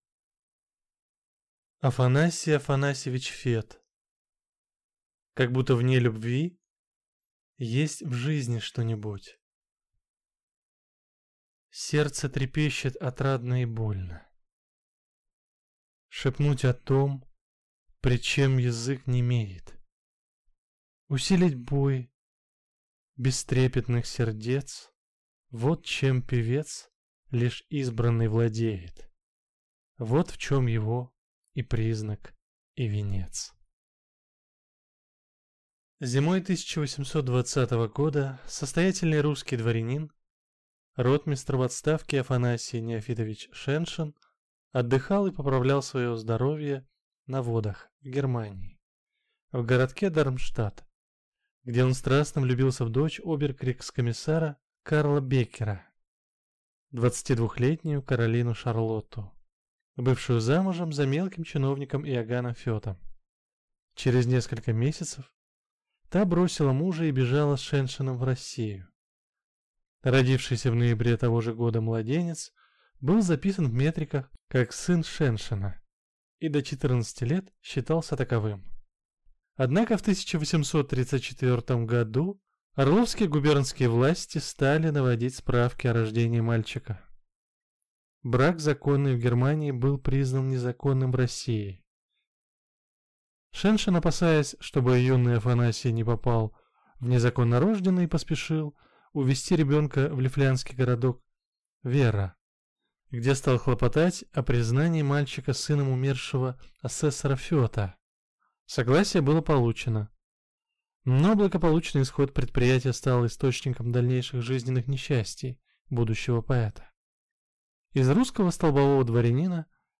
Аудиокнига Как будто вне любви есть в жизни что-нибудь…